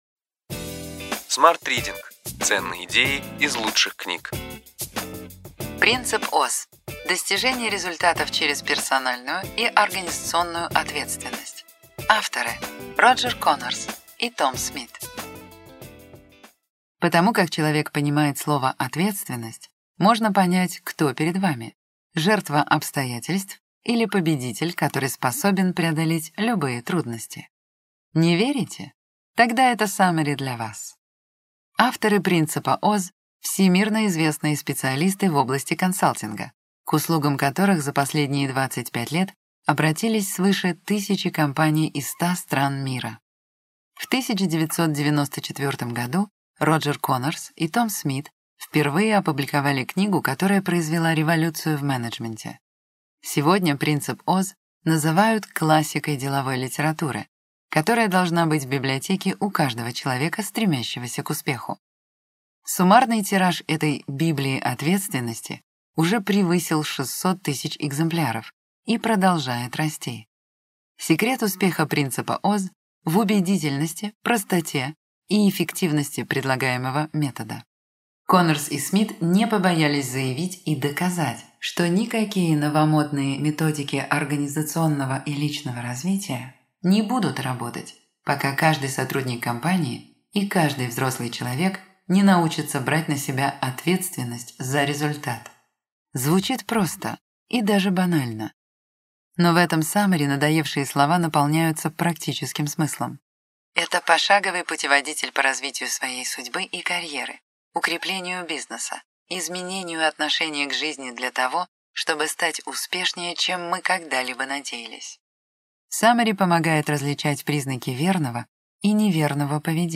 Аудиокнига Ключевые идеи книги: Принцип Оз. Достижение результатов через персональную и организационную ответственность.